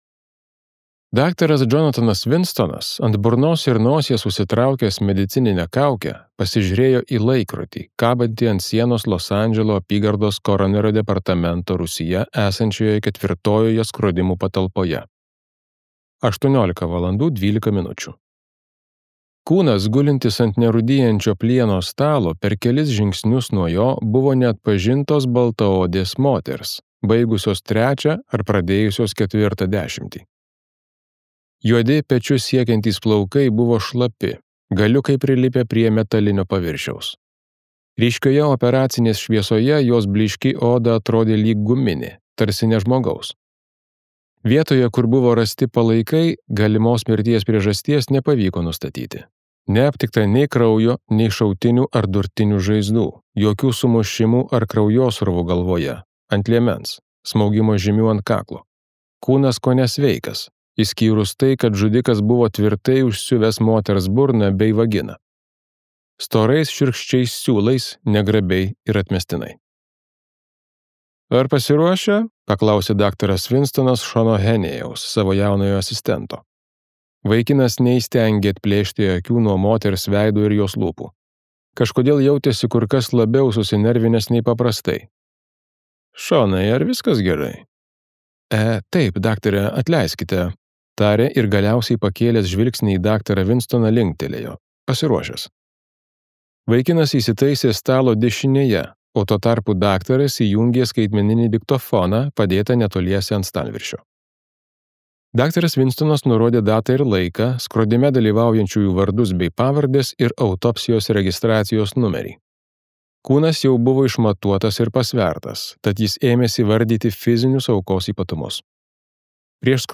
Chris Carter audioknyga „Šešėlis naktyje“ – dar viena prikaustanti istorija. Kai randamas moters lavonas užsiūtais lytiniais organais ir groteskiška „dovanėle“ viduje – detektyvas Robertas Hanteris atšaukiamas iš visų bylų narplioti šios.